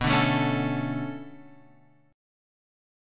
B Minor 9th